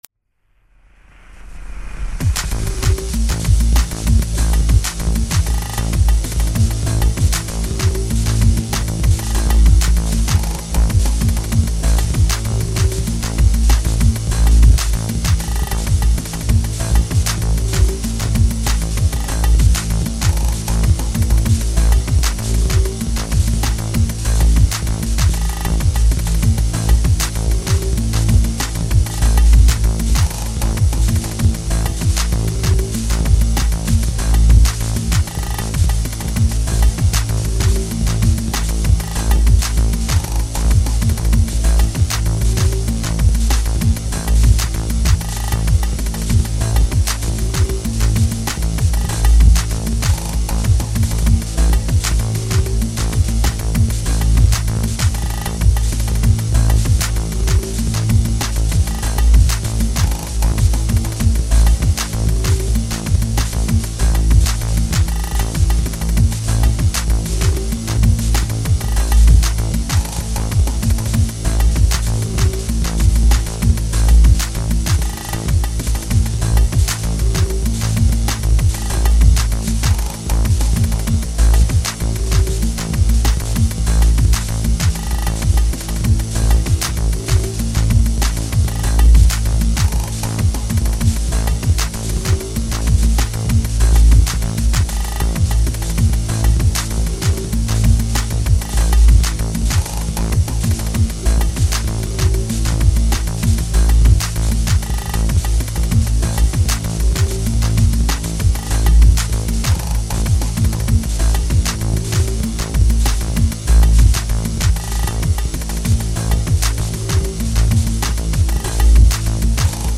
File under: Avantgarde
introduces more rhythmic layers.